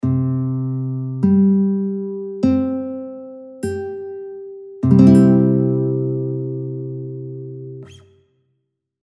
Акорд давнього строю – до-соль-до-соль, CGCG (mp3):
Bandurka_C-strij_DAVNIJ_Akord_CGCG.mp3